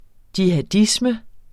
Udtale [ djihaˈdismə ]